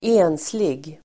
Uttal: [²'e:nslig]